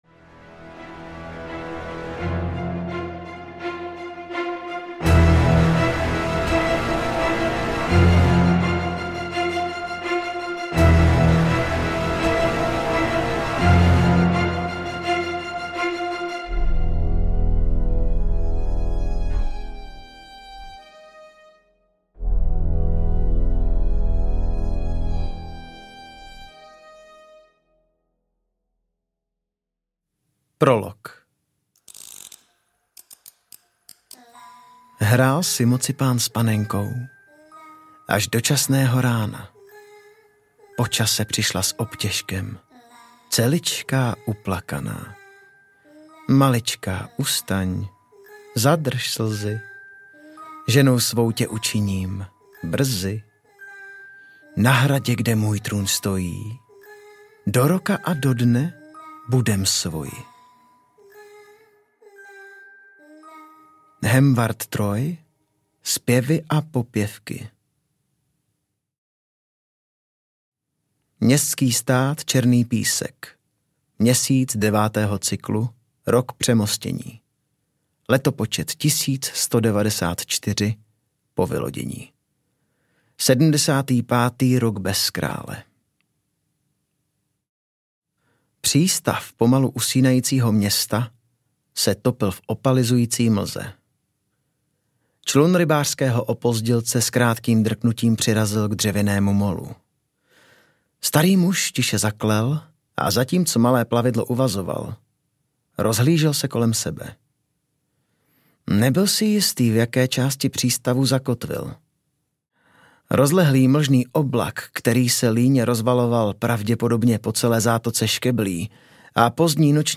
Zloděj osudů audiokniha
Ukázka z knihy